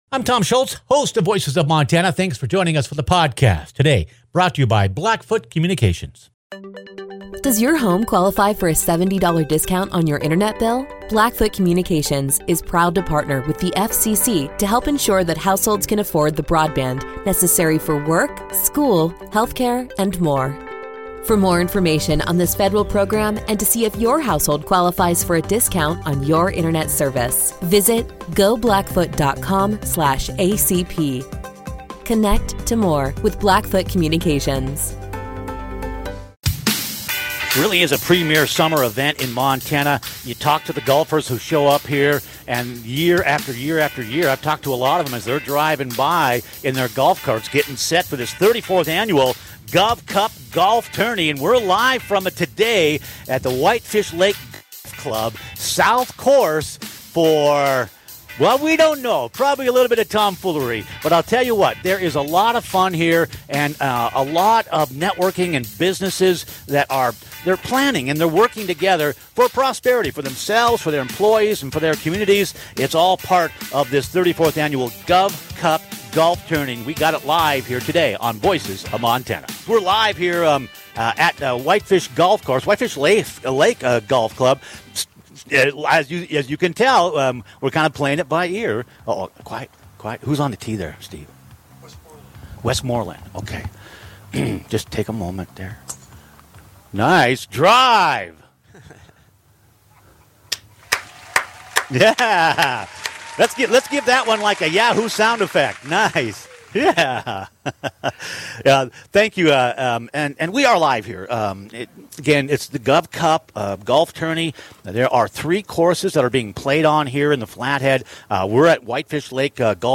Broadcasting LIVE from the Governor’s Cup Golf Tournament - Voices of Montana